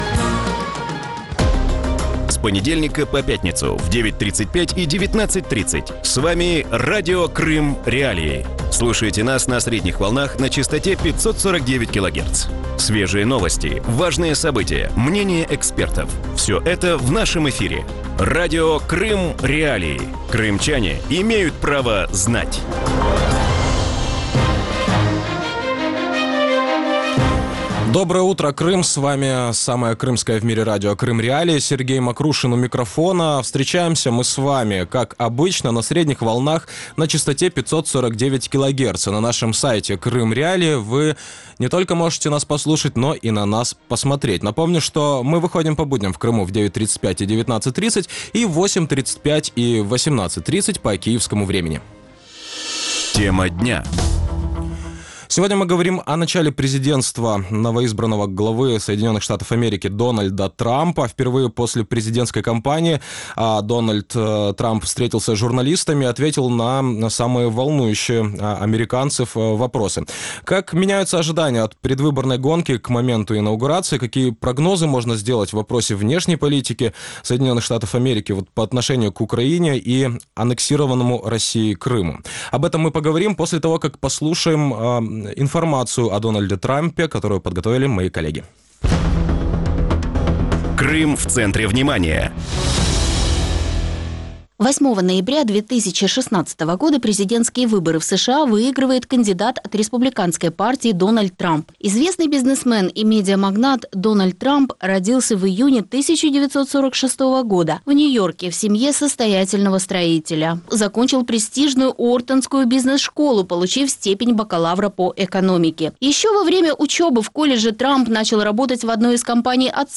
В утреннем эфире Радио Крым.Реалии говорят о президентстве новоизбранного главы США Дональда Трампа.